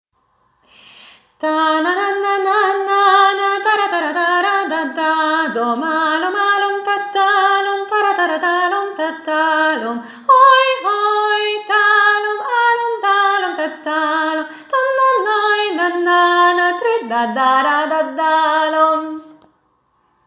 Az ének-zenei nevelés óvodai módszertanának e területén különösen nagy a hiányosság, s ezt az elmaradást igyekszik pótolni a tanulmány egy autentikus vokális cigány népzenei anyag gyűjteményével.